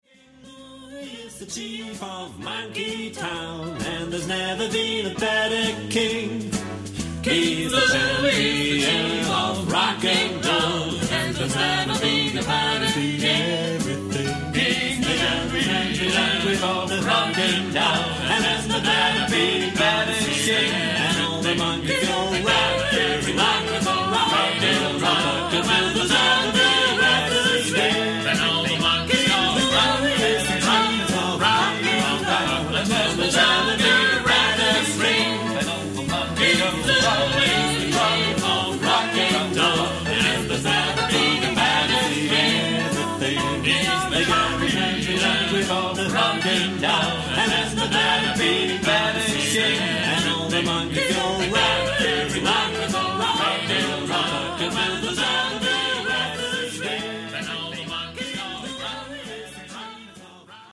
Genre-Stil-Form: Kanon ; Rock ; weltlich
Chorgattung:  (4-stimmiger gleichstimmig )
Tonart(en): A-Dur